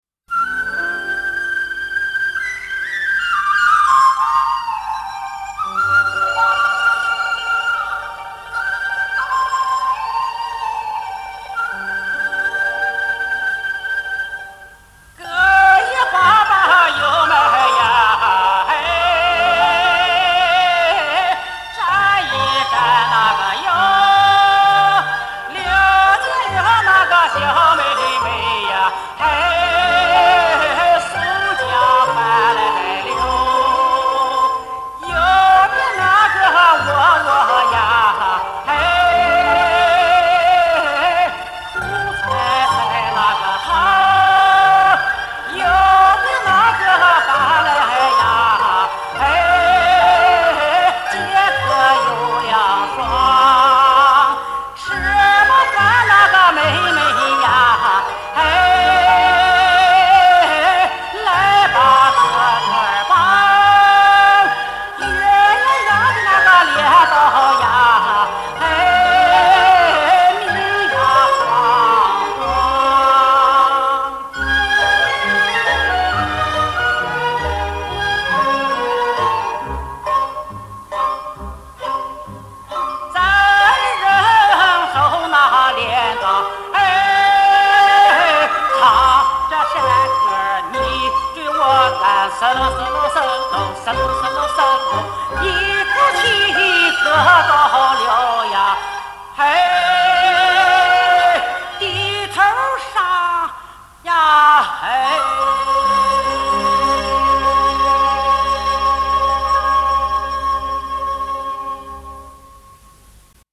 [3/2/2021]著名男高音歌唱家吕文科演唱的山西民歌《割莜麦》 激动社区，陪你一起慢慢变老！